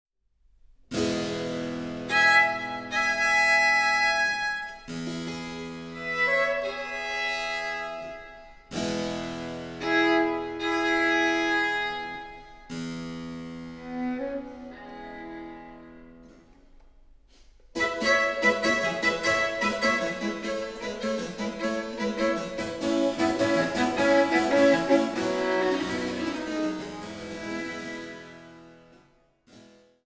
Sonate Nr. 3 F-Dur für Violine und B.c. (1681)